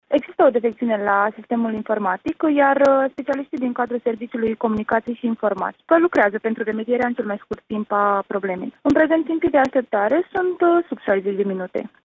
a declarat pentru postul nostru de radio, că defecţiunea va fi remediată în cel mai scurt timp.